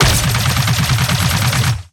Added more sound effects.